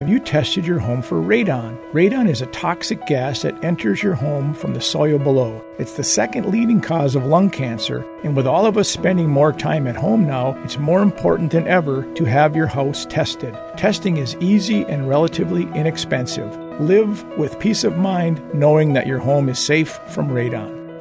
Radon Testing Ad 1